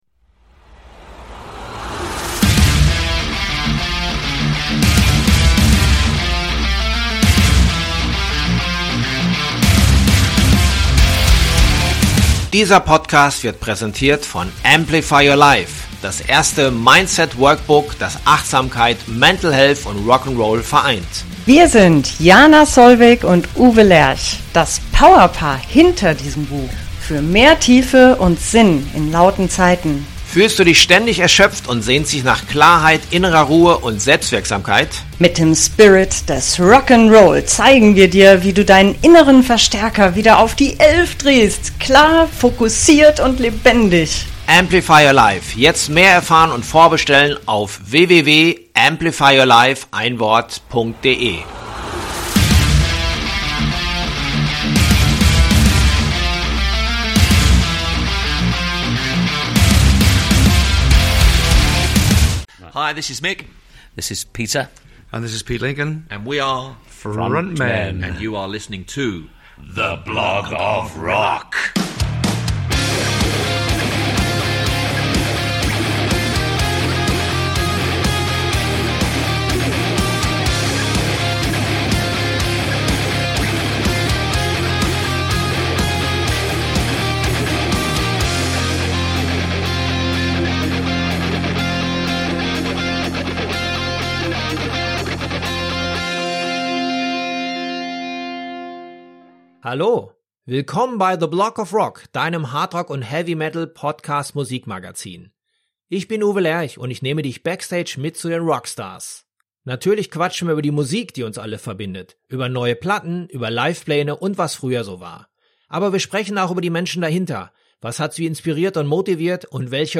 sehr harmonielastigen Gesangs
einer überwiegend akustischen Instrumentierung